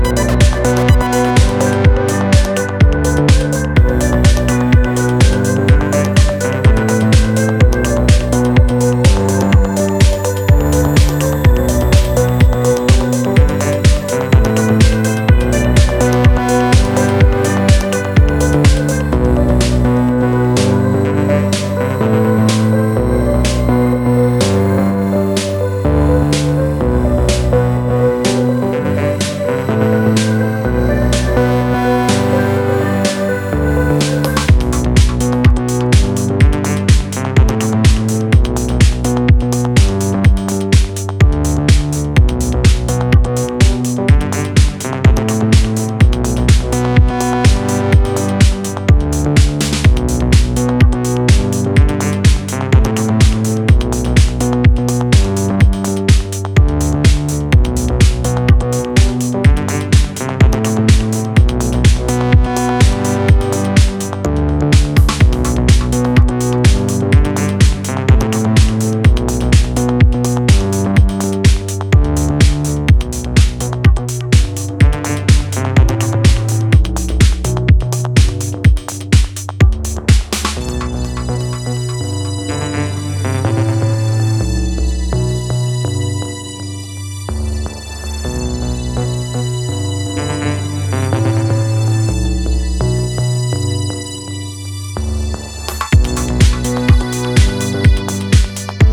jacking drum machine electro-techno